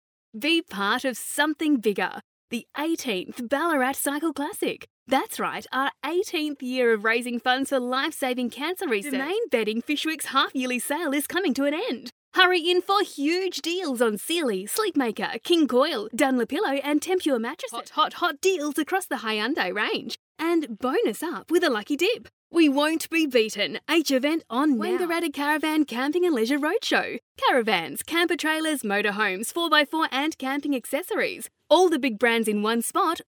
20s - 30s
Female
Australian
Natural
Hard Sell
Narration